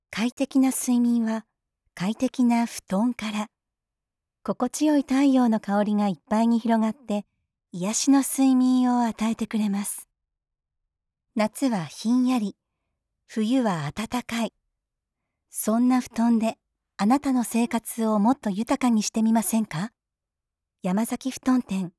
しっかり内容を理解し、印象的かつ聞きやすい声で聴き手に伝わるナレーションをお届けします。
クールな、かっこいい